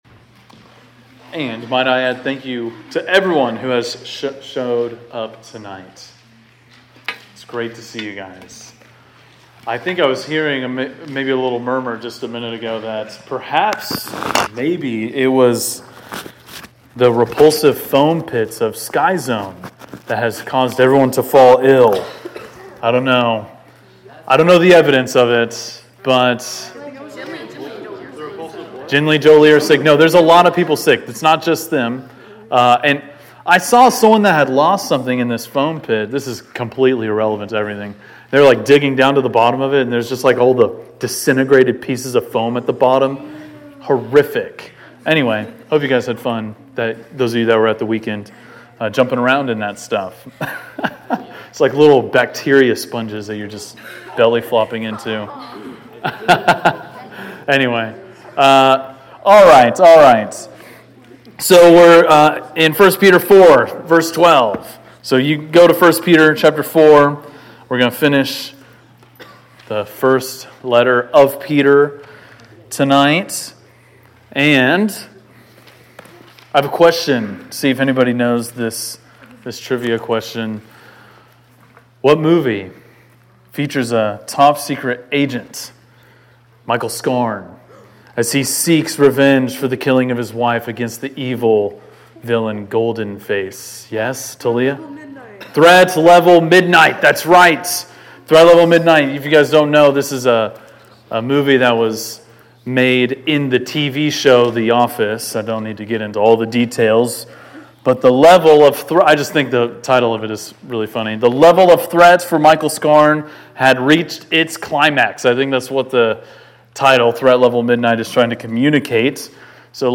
preaches on 1 Peter 4:12-5:14.